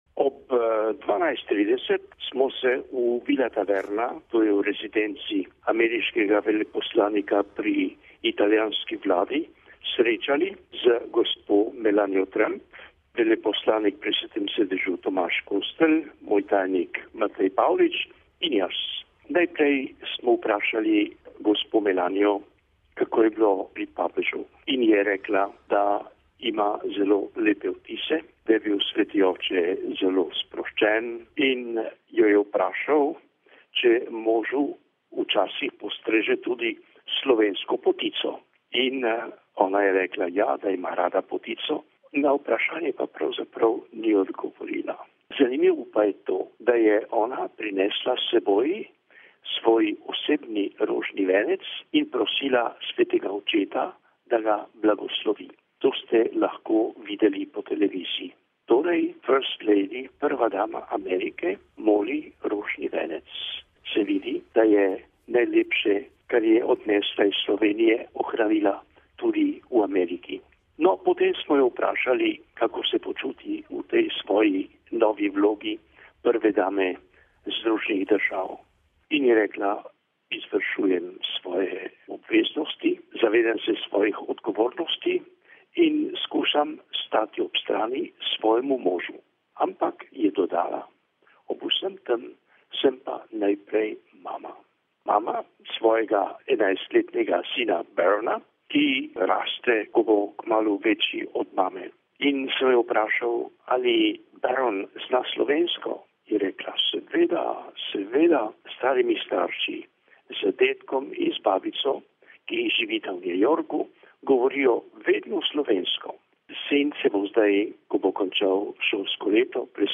Poklicali smo kardinala in ga prosili, da za Radio Vatikan spregovori o svojem srečanju s prvo damo ZDA.